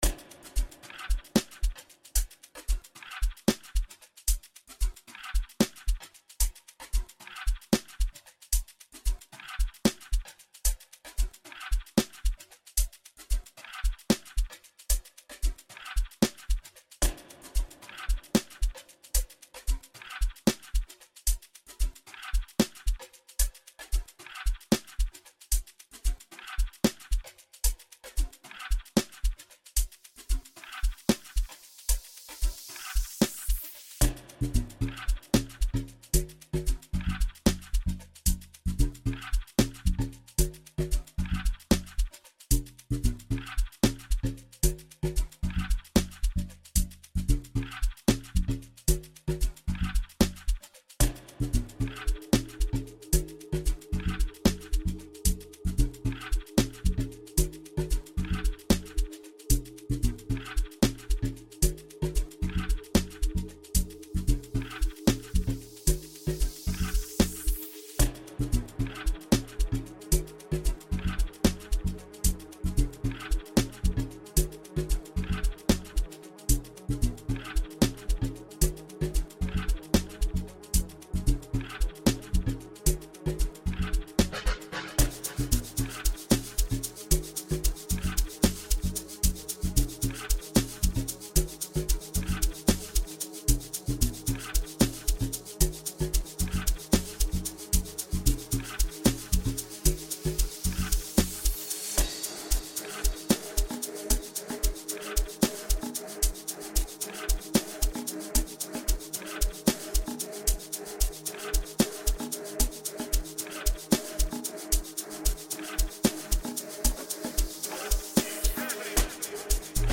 instrumental Sgidongo package